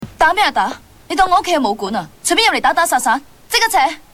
This is the scene where Ip Man’s wife has just totally had it with people having fights in her house. She’s not having any more of it and lets herself be heard.